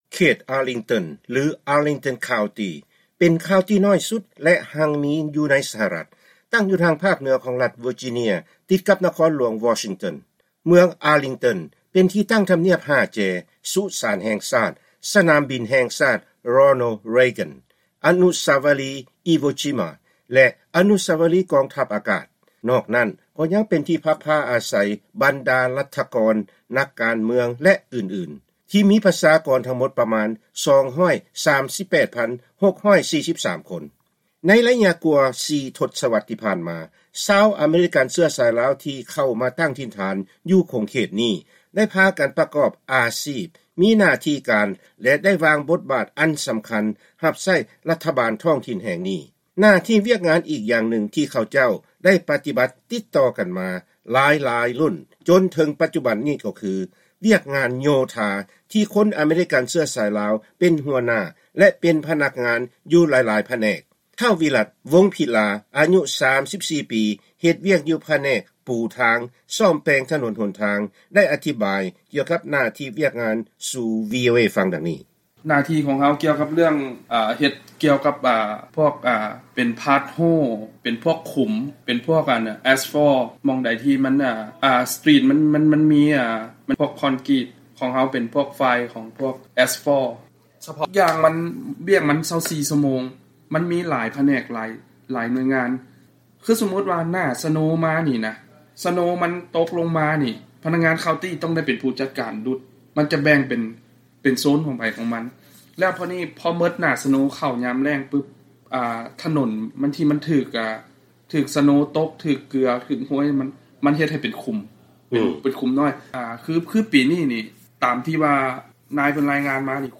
ວີໂອເອ ໂອ້ລົມກັບທີມໂຍທາ ລາວອາເມຣິກັນ ຢູ່ອາລິງຕັນຄາວຕີ